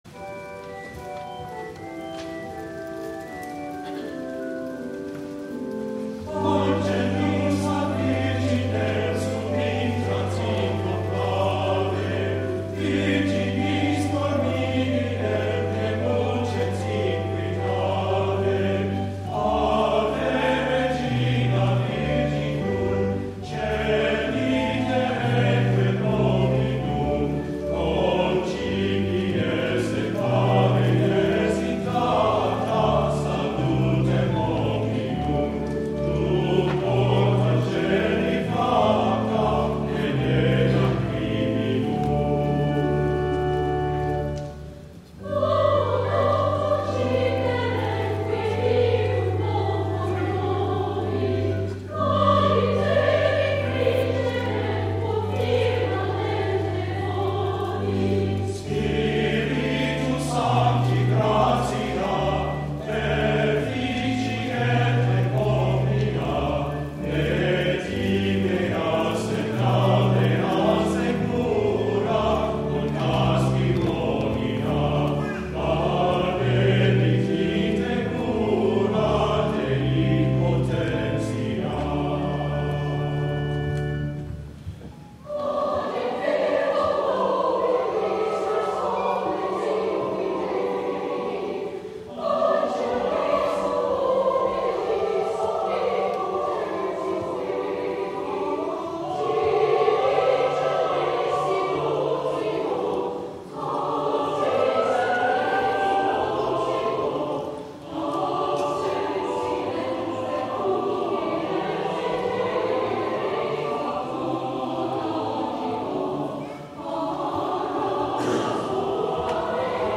11 A.M. WORSHIP
Angelus ad virginem  14th-century Irish carol/arr. David Willcocks